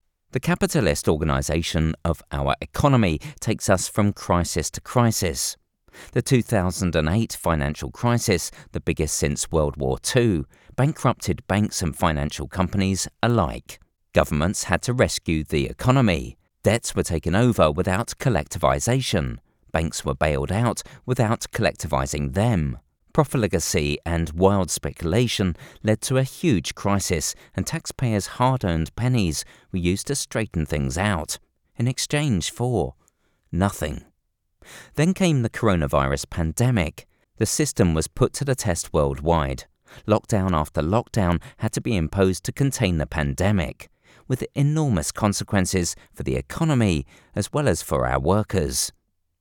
Anglais (britannique)
Livres audio
Neumann TLM-103
Cabine insonorisée
Âge moyen
Baryton